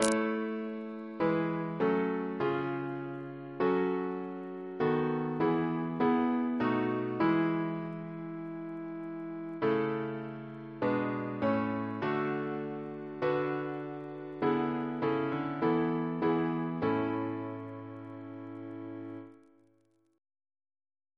Double chant in A Composer: John Jones (1757-1833), Organist of St. Paul's Cathedral Reference psalters: ACB: 158; ACP: 292; CWP: 167; OCB: 29; PP/SNCB: 80; RSCM: 81